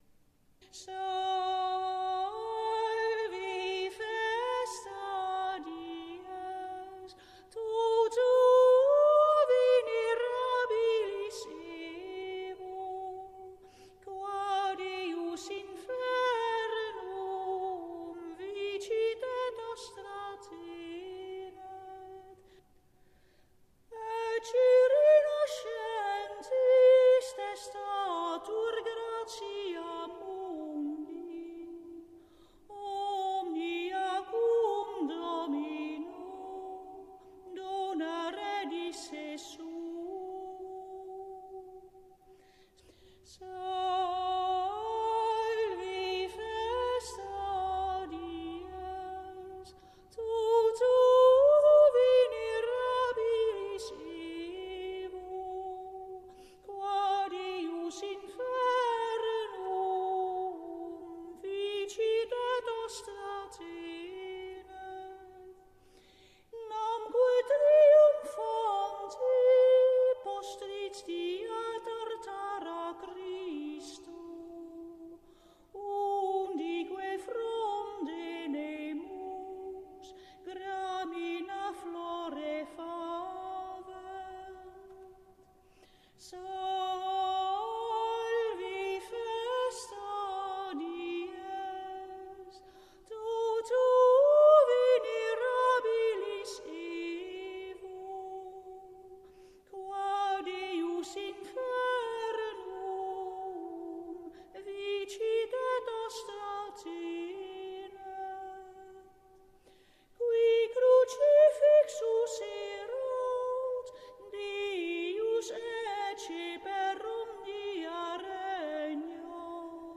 Overige gezangen